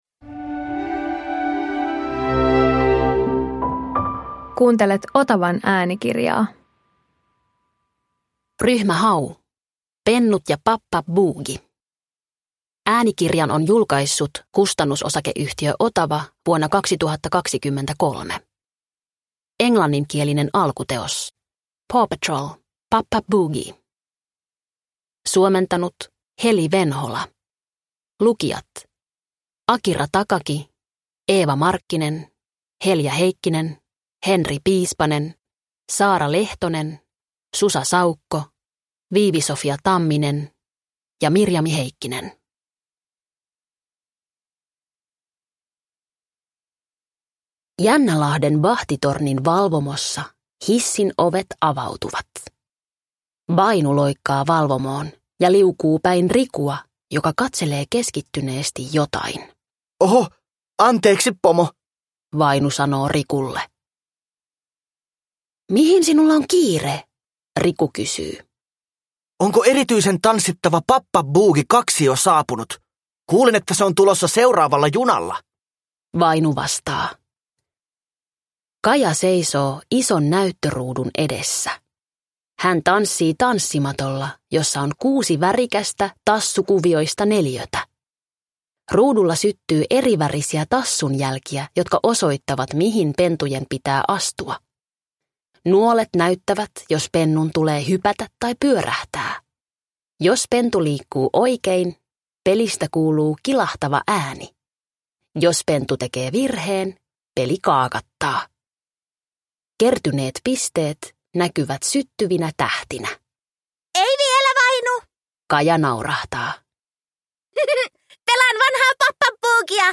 Ryhmä Hau Pennut ja Pap-pap-buugi – Ljudbok – Laddas ner